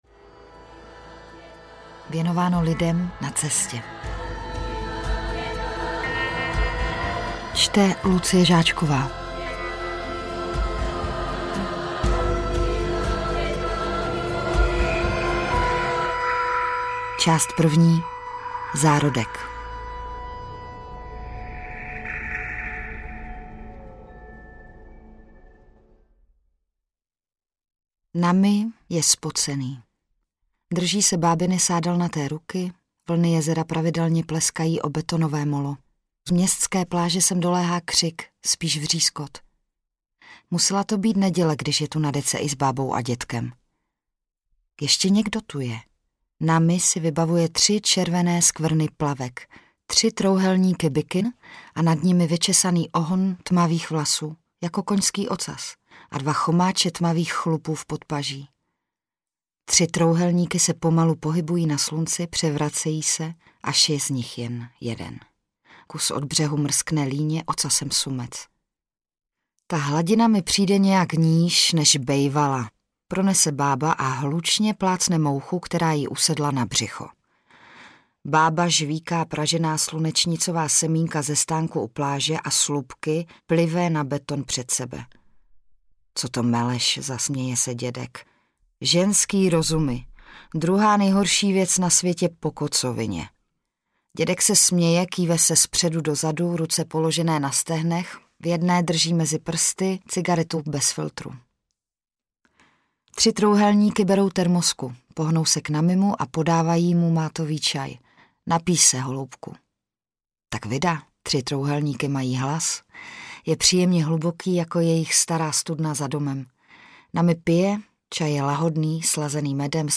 Jezero audiokniha
Ukázka z knihy
• InterpretLucie Žáčková